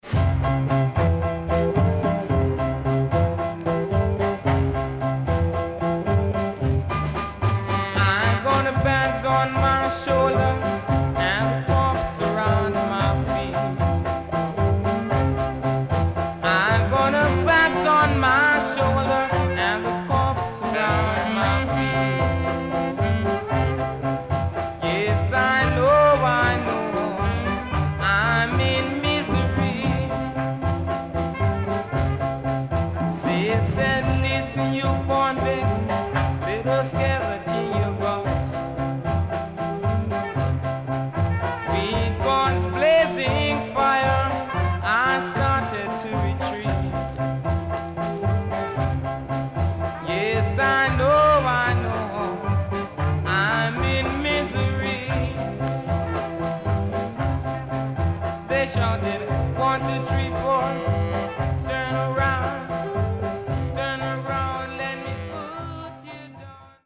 HOME > Back Order [VINTAGE 7inch]  >  SKA  >  EARLY 60’s
SIDE A:少しチリノイズ入りますが良好です。